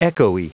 Prononciation du mot echoey en anglais (fichier audio)
Prononciation du mot : echoey